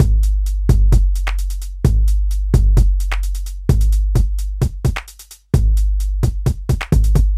电鼓循环播放 " elctro 02 hat 120
描述：droom loops和建筑套件。
标签： 节拍 鼓环 博士UMS 循环 样品
声道立体声